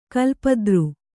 ♪ kalpadru